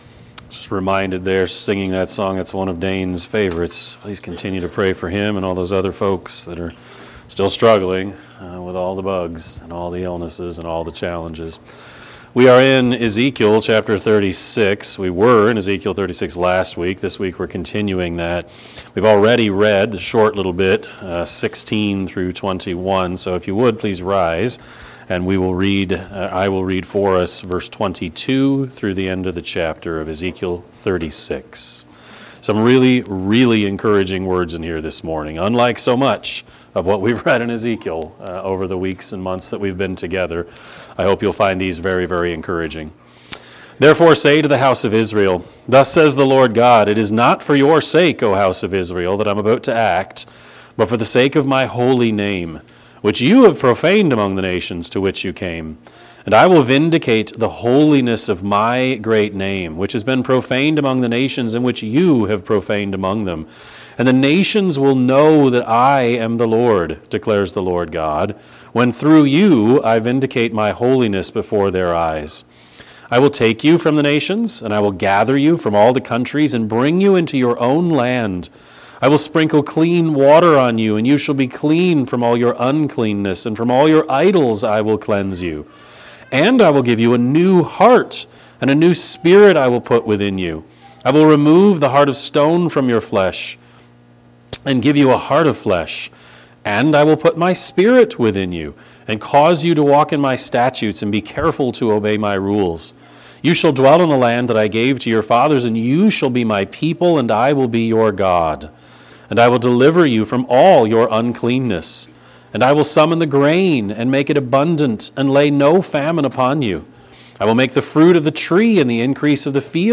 Sermon-2-23-25-Edit.mp3